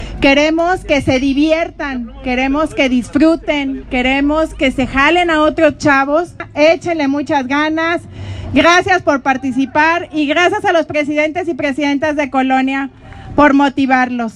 AudioBoletines
Lorena Alfaro García – Presidenta de Irapuato